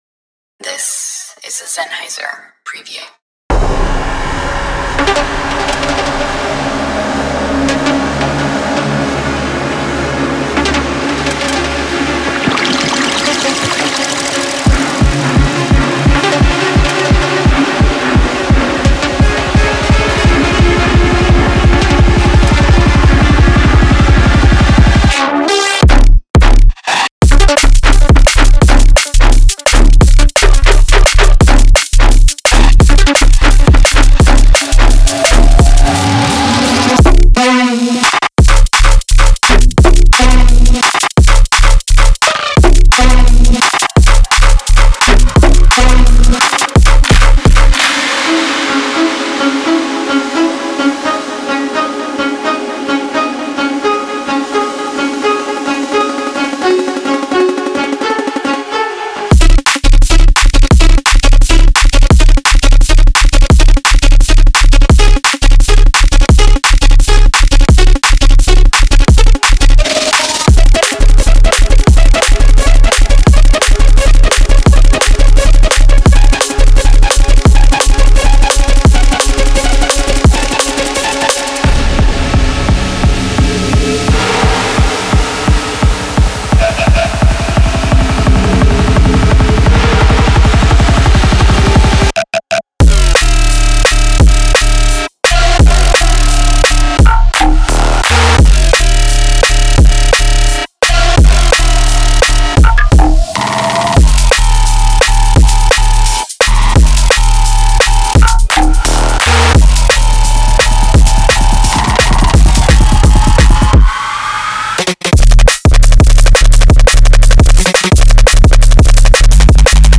Genre:Drum and Bass
スピーディで刺激的、しかも予想を超えるサウンドが満載です。
パックには100種類のベースループが収録されており、重厚なローラーと獰猛なグロウラーに分かれています。
鋭くグリッチーなアタックから、広がりのあるメロディックなフレーズまで、ビートとベースに浮かぶように展開します。
デモサウンドはコチラ↓